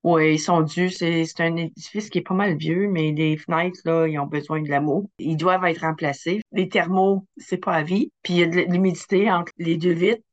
Carole Robert, mairesse de Low, explique pourquoi la Municipalité procède à ces travaux :